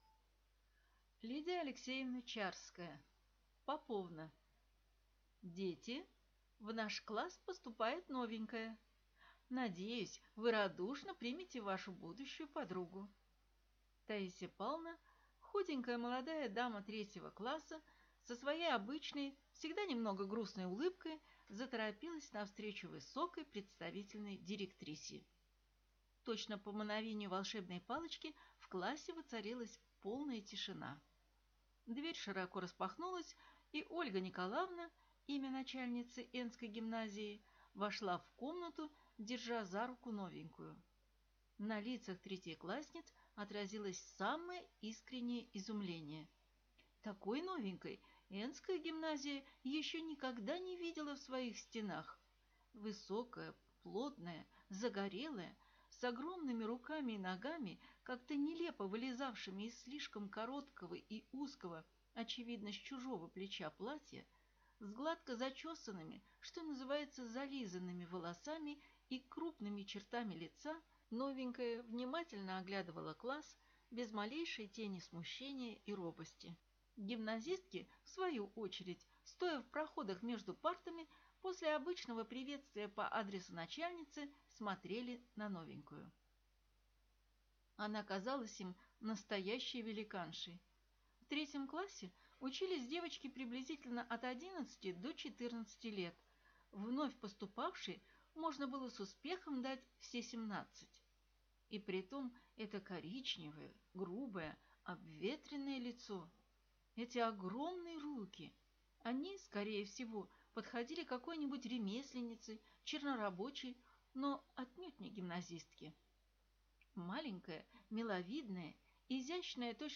Аудиокнига Поповна | Библиотека аудиокниг